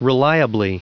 Prononciation du mot reliably en anglais (fichier audio)
Prononciation du mot : reliably